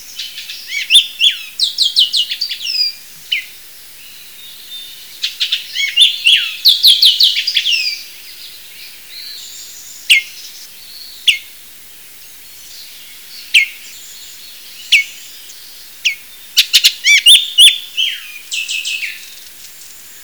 Bullock's Oriole
Icterus bullockii